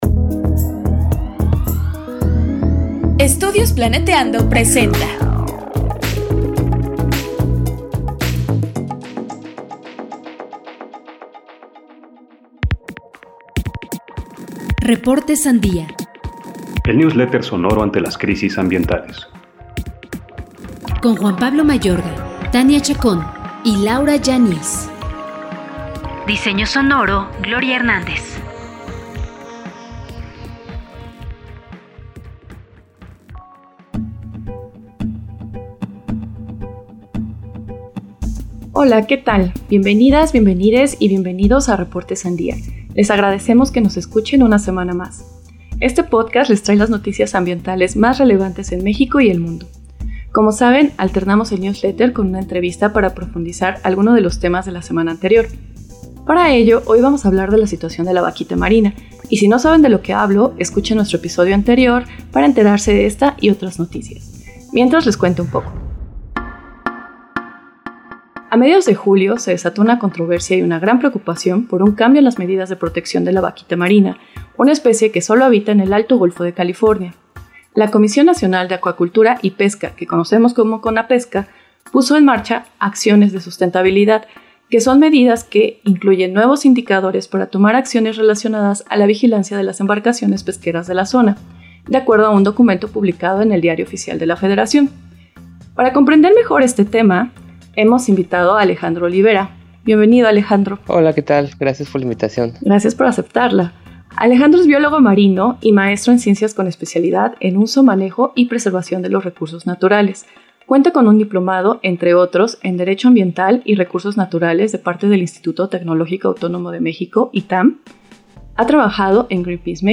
RS-ENTREVISTA-10-mezcla.mp3